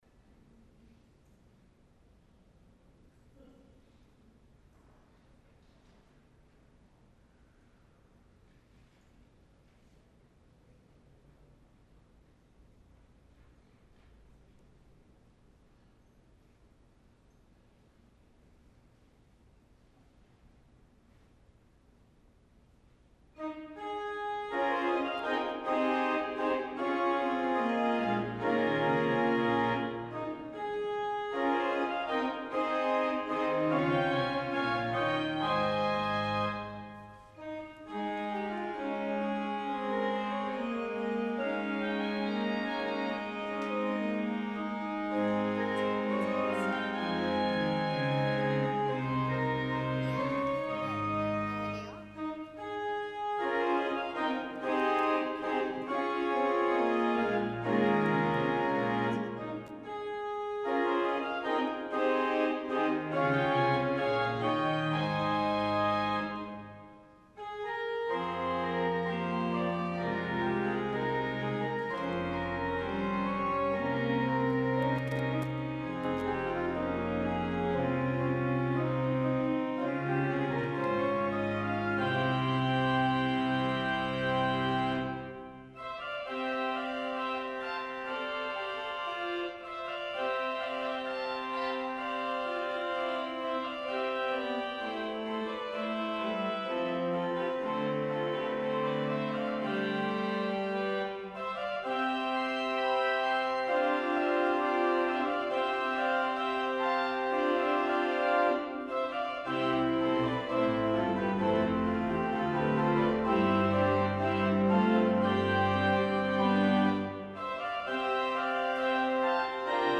Sunday Worship 3-28-21 (Palm Sunday)